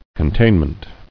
[con·tain·ment]